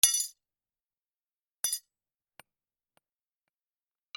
金属 置く
/ M｜他分類 / L01 ｜小道具 / 金属